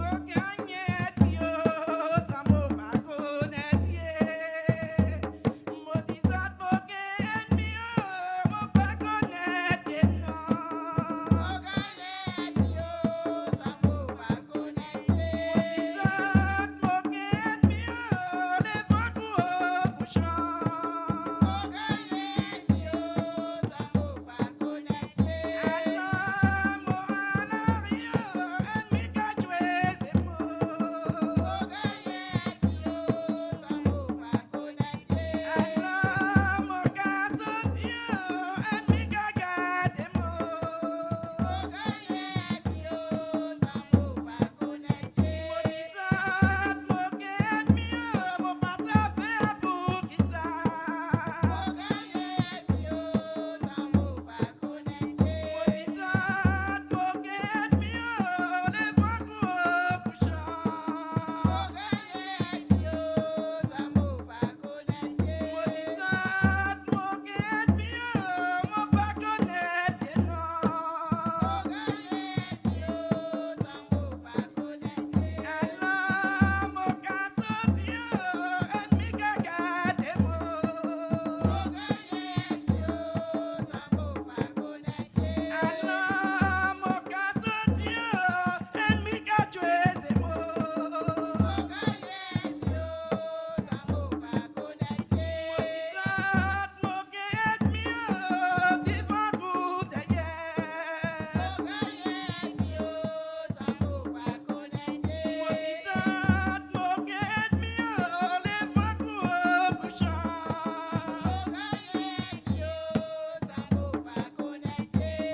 danse : grajévals (créole)
Pièce musicale inédite